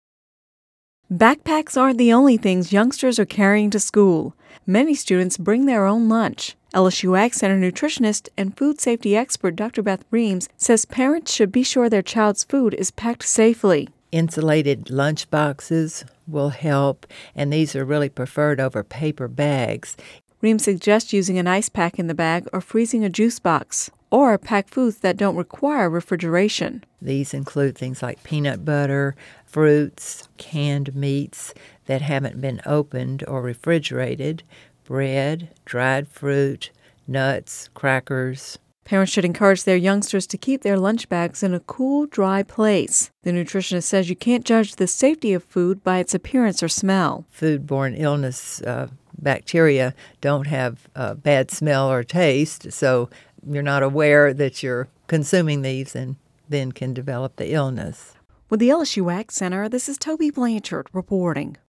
(Radio News 08/23/10) Backpacks aren’t the only things youngsters are carrying to school. Many students bring their own lunches.